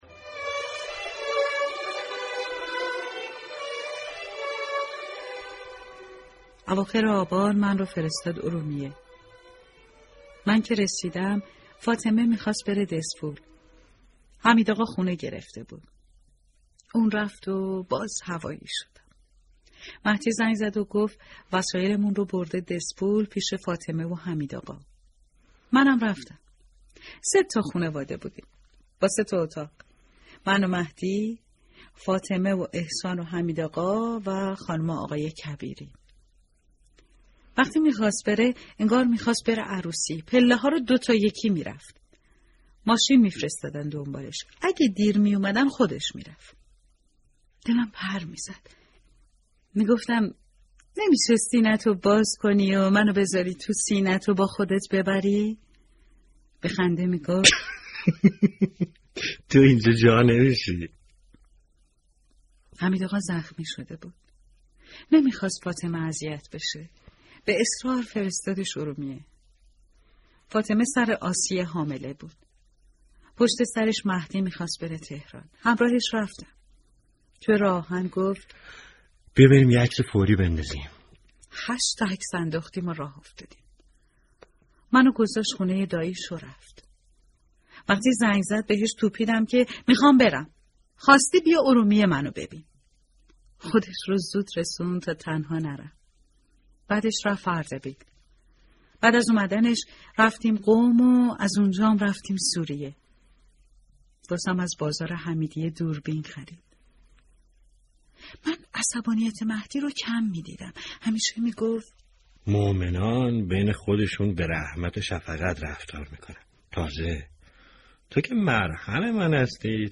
صوت روایتگری
ravayatgari50.mp3